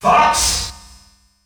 The announcer saying Fox's name in French releases of Super Smash Bros.
Category:Fox (SSB) Category:Announcer calls (SSB) You cannot overwrite this file.
Fox_French_Announcer_SSB.wav